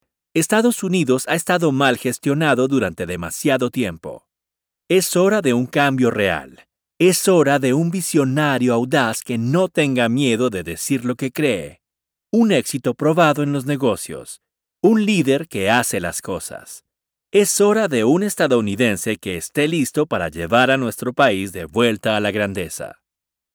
Spanish-speaking male voice actor
Spanish-Speaking Men, Political